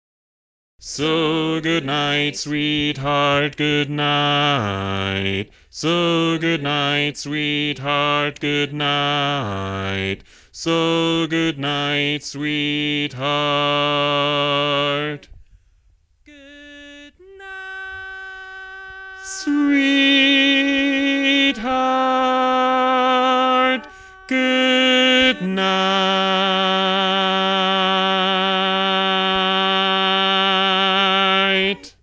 Key written in: G Major
Type: Barbershop
Each recording below is single part only.